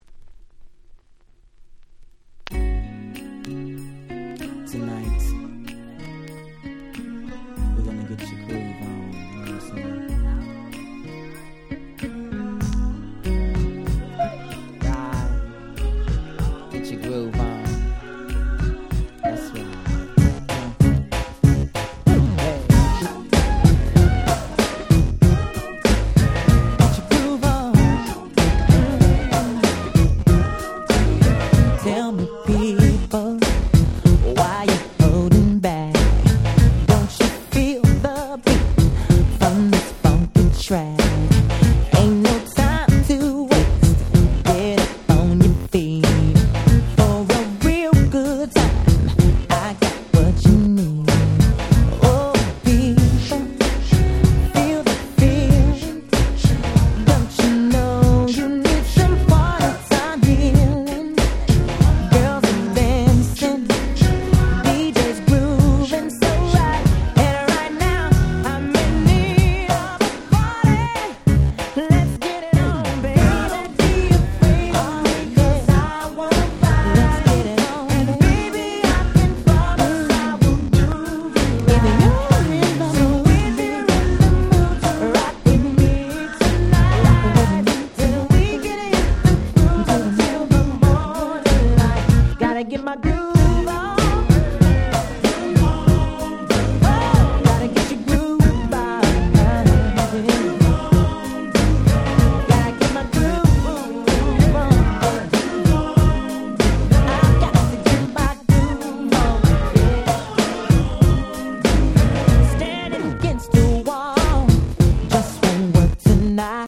94' Super Nice R&B !!
イントロからビーヒャラ言っちゃってもう最高の1曲ですね！！